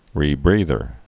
(rē-brēthər)